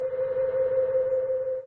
balloons_floating.ogg